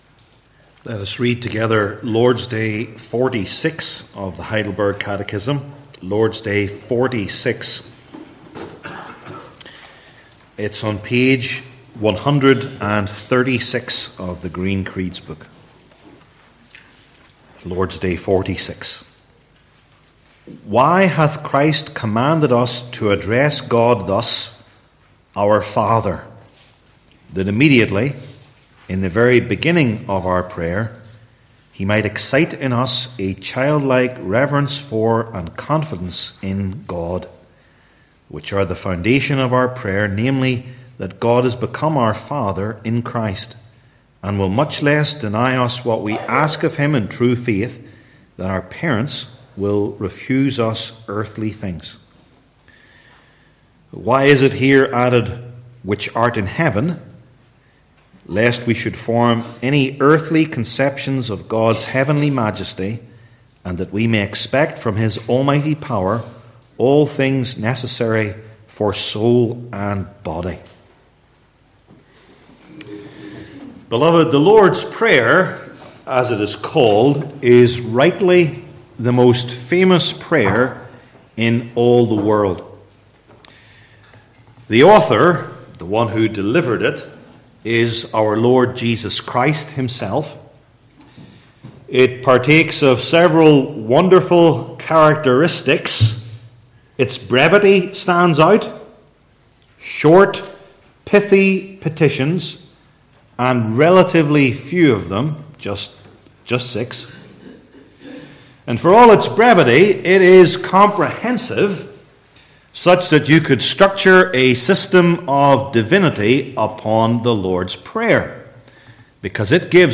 Matthew 6:1-18 Service Type: Heidelberg Catechism Sermons I. What It Means That God Is Our Father II.